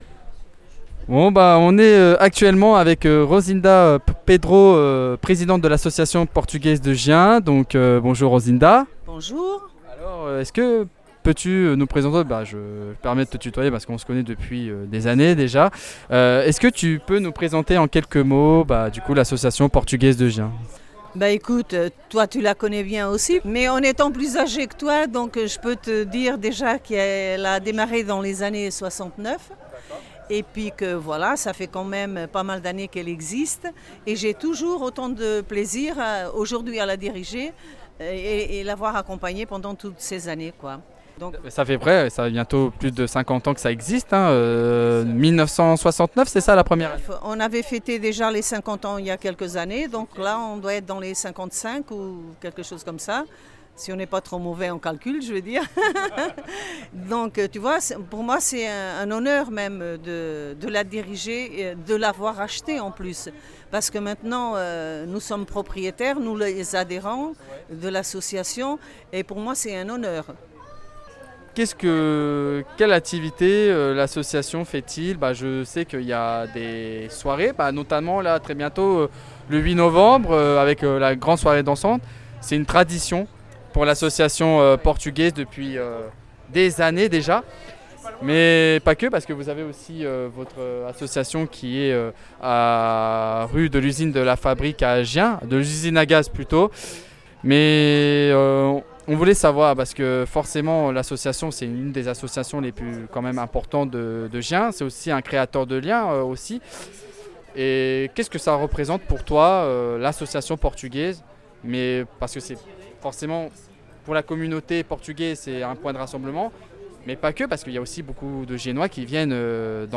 Fête des associations de Gien 2025 - Association Franco-Portugaise de Gien
Un échange chaleureux qui rappelle combien l’ouverture culturelle et la convivialité nourrissent la richesse associative locale.